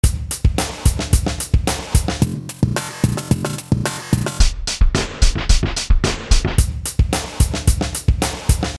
ピッチ変化 （MP3 140KB） オリジナル → +12 → -12 → オリジナル
生系ドラムのピッチを変化させると、独特の音になりますね。
drumloop_02.mp3